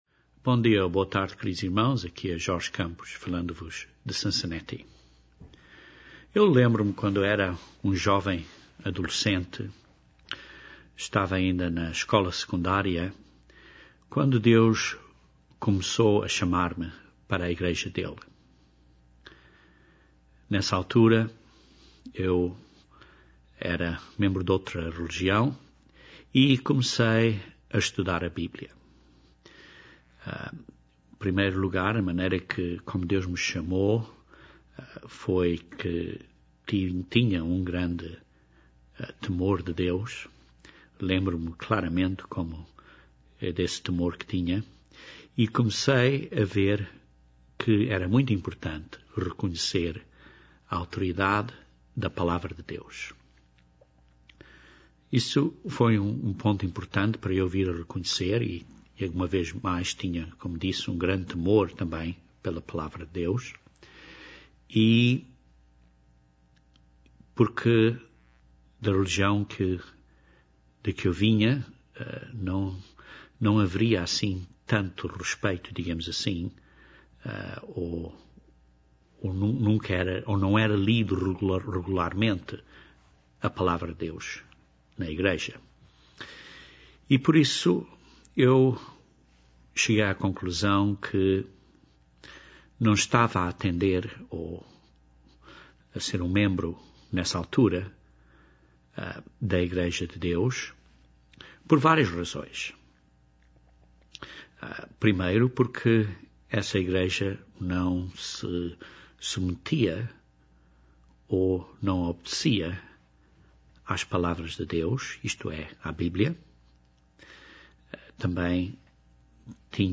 Esta doutrina é, talvez, a mais simples de provar da Bíblia. Este sermão aborda os seguintes pontos: - Foi a ciclo da semana perdido, ou sabemos que de facto o sétimo dia da semana é o Sábado?